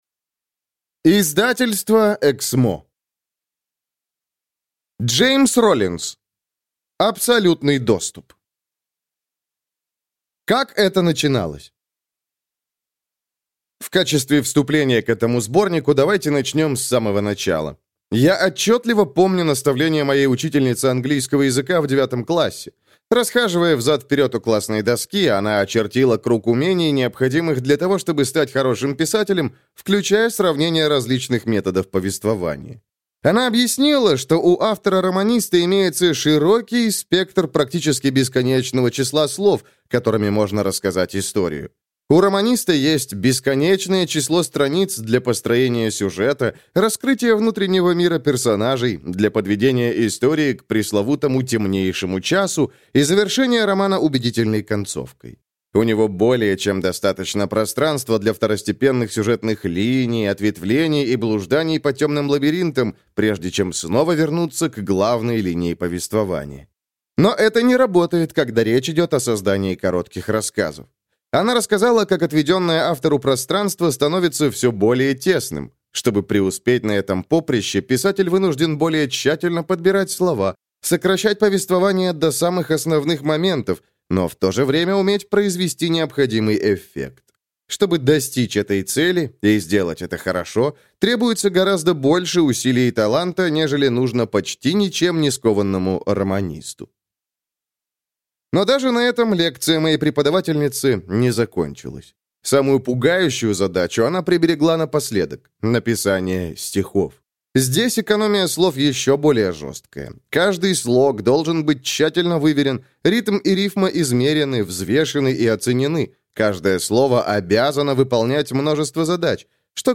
Аудиокнига Абсолютный доступ | Библиотека аудиокниг